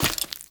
sounds / mob / bogged / step2.ogg
step2.ogg